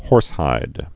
(hôrshīd)